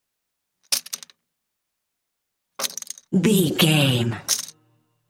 Casino 5 chips table x3
Sound Effects
foley